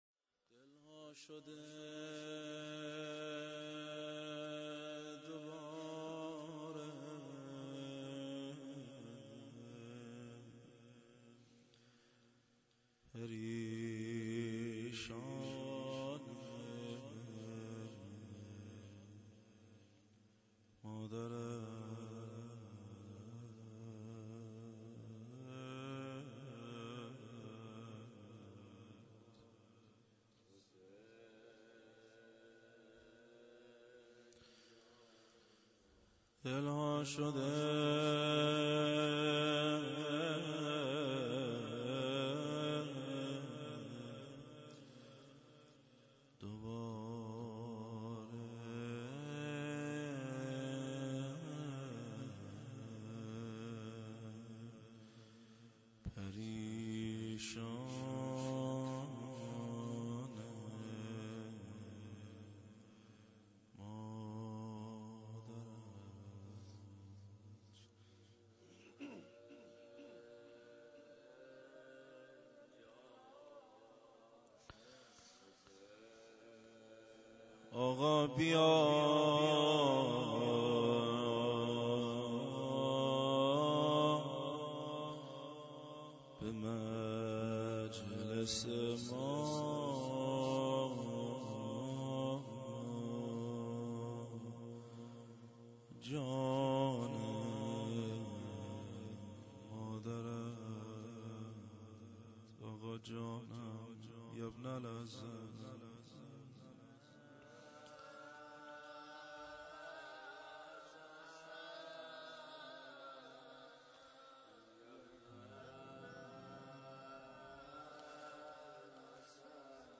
توسل هفتگی-روضه حضرت زهرا(س)-27 بهمن 1396